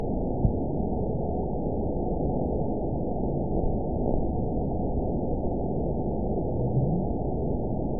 event 922909 date 05/03/25 time 07:25:43 GMT (1 month, 2 weeks ago) score 9.30 location TSS-AB06 detected by nrw target species NRW annotations +NRW Spectrogram: Frequency (kHz) vs. Time (s) audio not available .wav